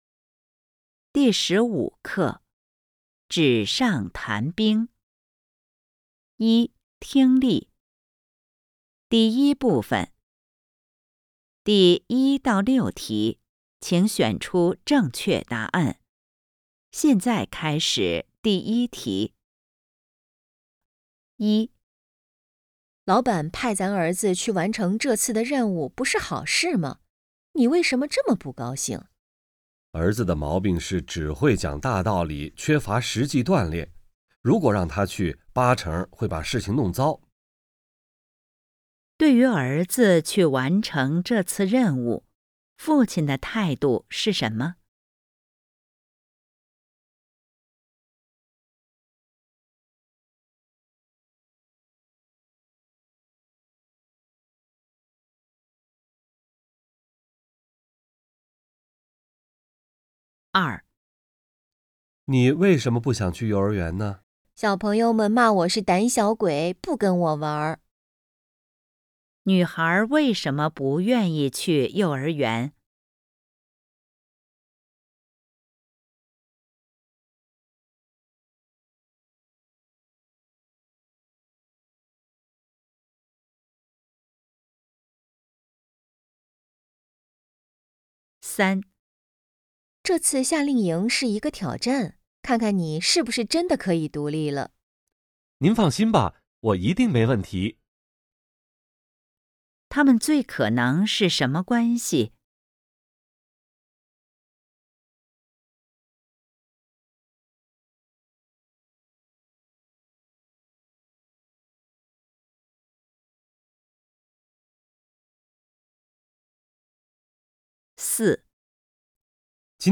一、听力